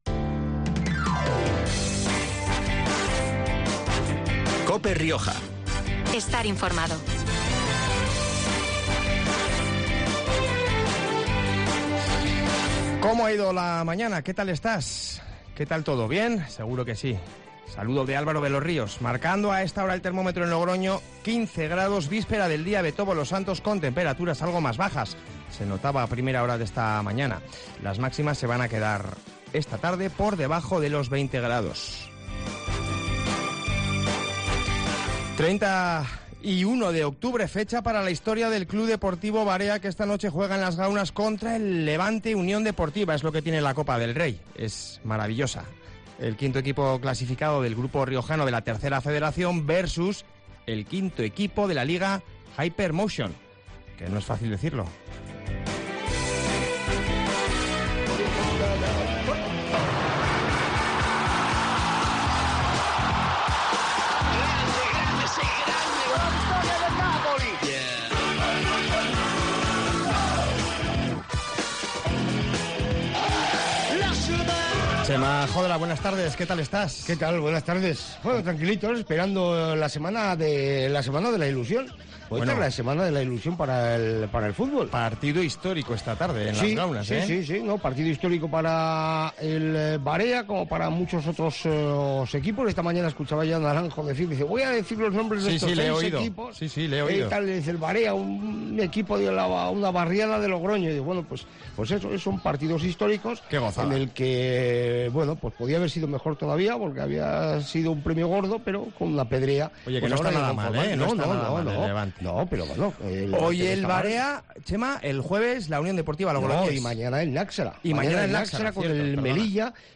ha pasado por los micrófonos de COPE horas antes del partido.